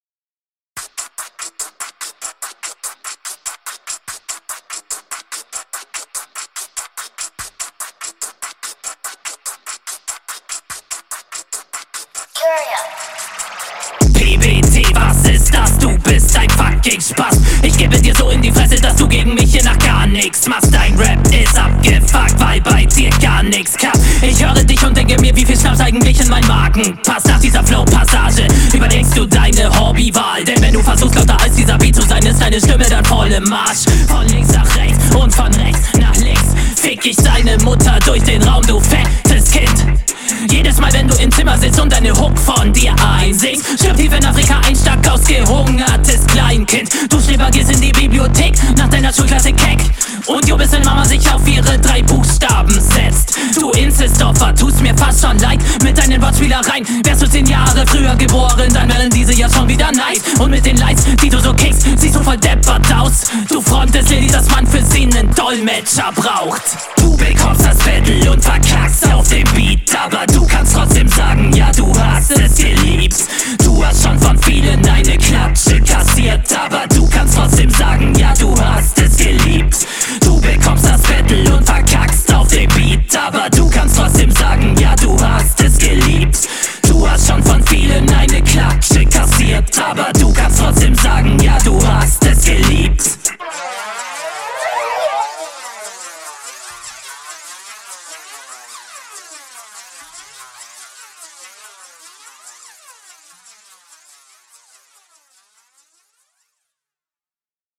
Geil, absolut anders, kein Standardflow.